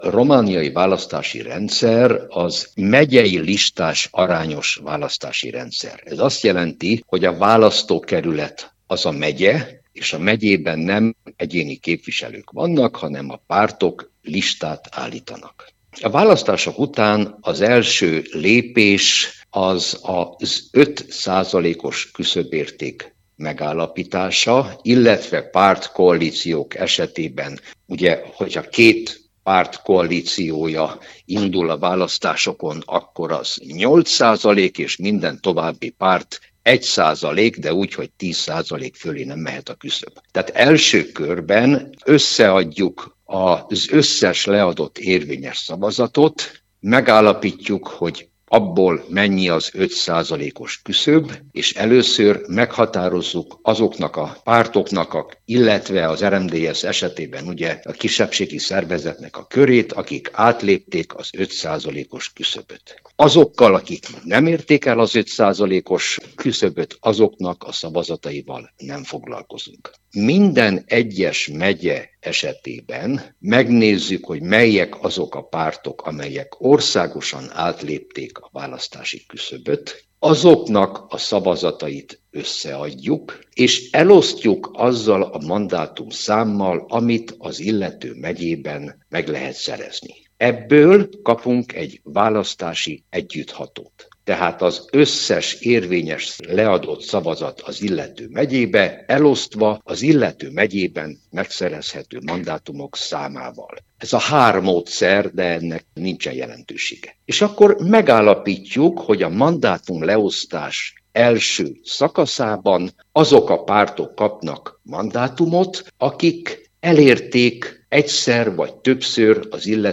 Az 1996-os parlamenti választások óta az RMDSZ-nek minden alkalommal volt „meglepetés mandátuma” – mondta el a Marosvásárhelyi Rádió által megkérdezett szociológus.